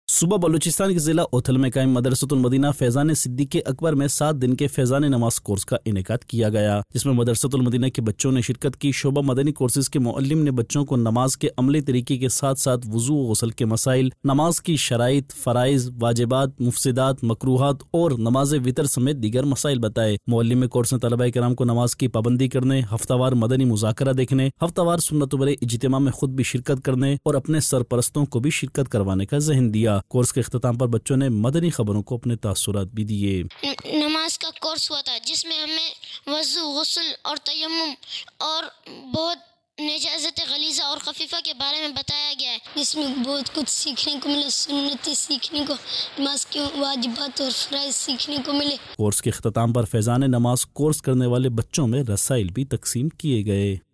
News Clips Urdu - 02 March 2023 - Madrasa tul Madina Faizan e Siddiq e Akbar Main Faizan Namaz Course Ka Ineqad Taliba Kiram Shareek Mar 9, 2023 MP3 MP4 MP3 Share نیوز کلپس اردو - 02 مارچ 2023 - مدرسۃ المدینہ فیضان صدیق اکبر میں فیضان نماز کورس کا انعقاد طلباء کرام شریک